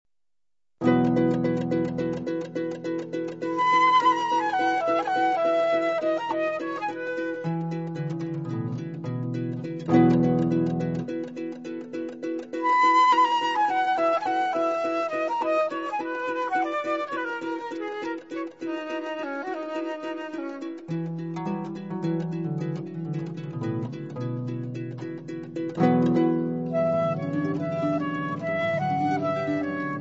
Per flauto e arpa. Cantilena - Nenia - Girotondo